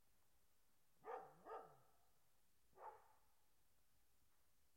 标签： barking dog growling pet woof